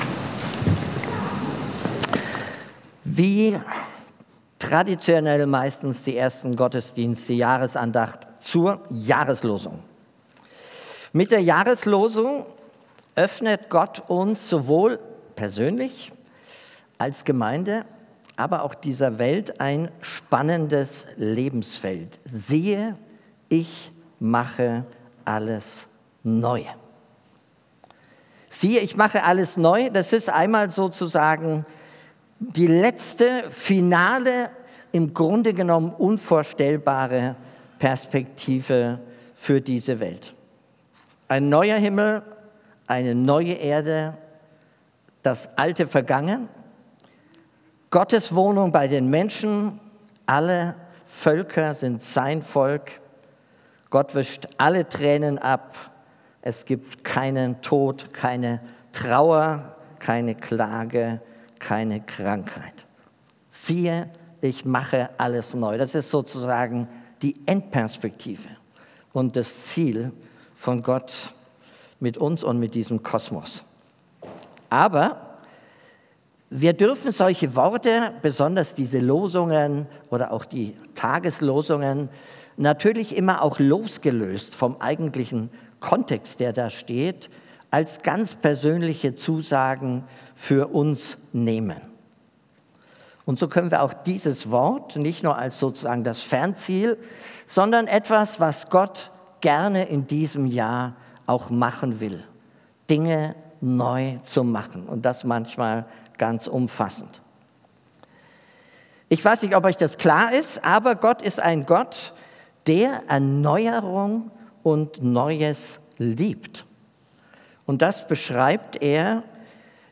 Gottesdienst mit Abendmahl
Predigt